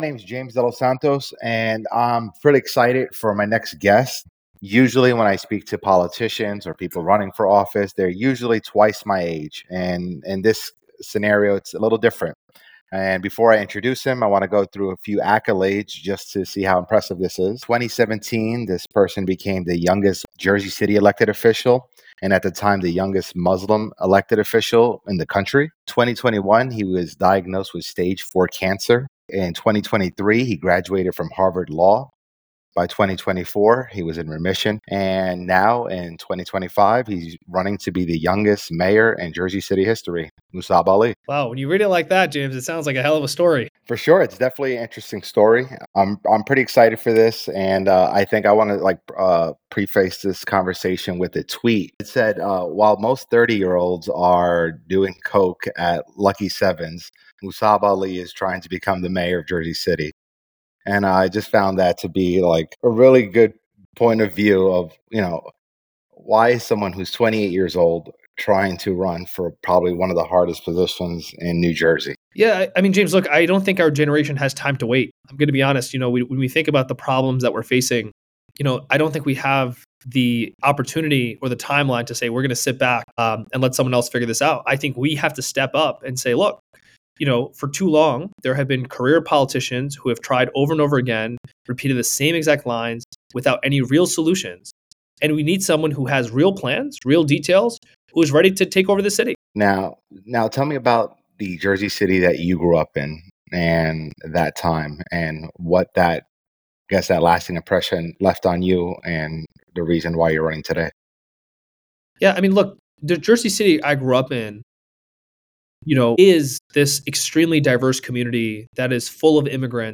Interview with Mussab Ali